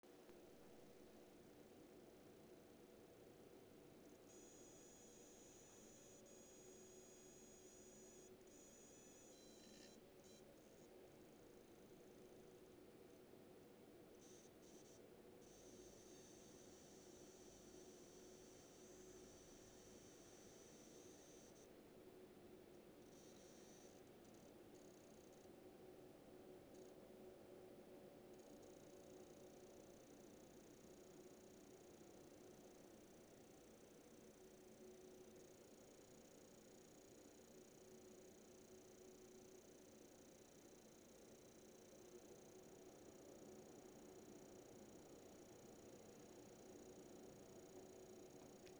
Anbei das Soundfile meiner Grafikkarte. Ab ca 4 Sek. beginnt Forza zu laden (dort hört man das Fiepen bei den vielen FPS in Ladescreens).
Ab da hört man dieses Eieruhr-mäßige Klackern/Rasseln/Ticken...